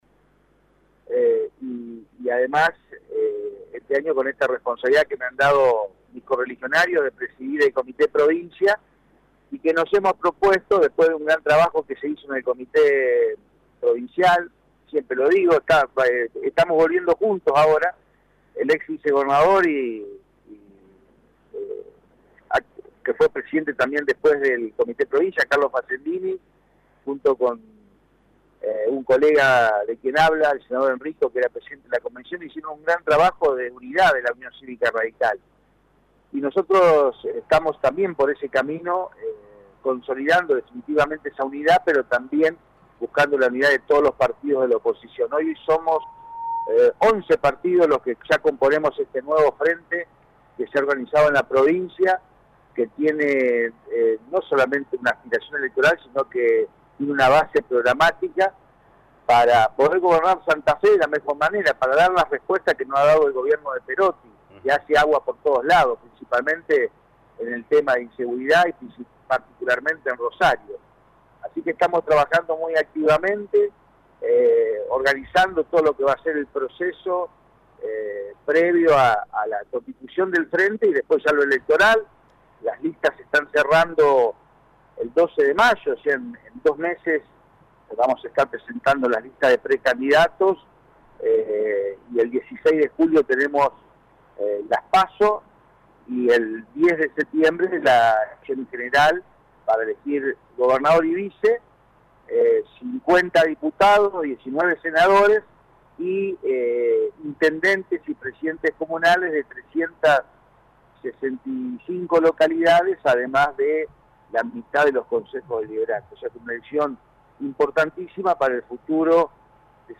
En diálogo con LA RADIO 102.9 FM el senador provincial por Santa Fe Felipe Michlig manifestó estar trabajando para que el próximo gobernador de la provincia vecina sea de la Unión Cívica Radical.